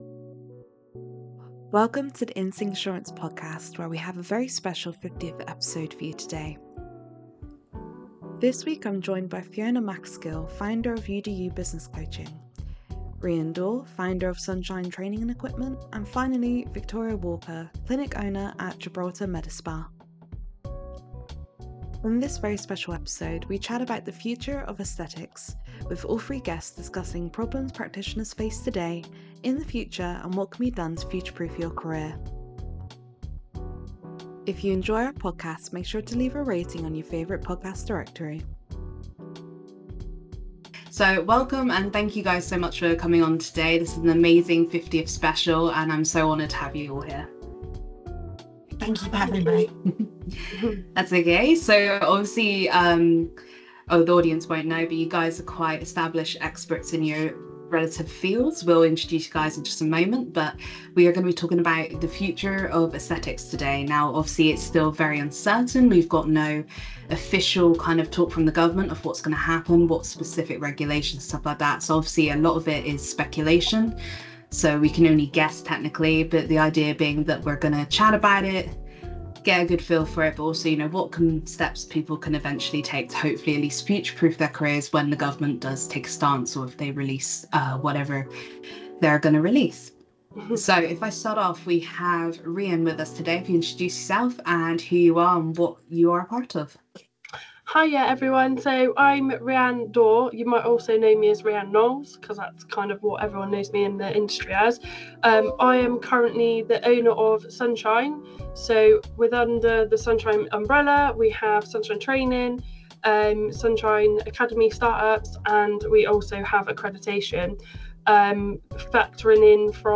In this special 50th episode, we chat about the Future of Aesthetics, with all three guests discussing the problems practitioners face today, in the future and what can be done to future-proof your career.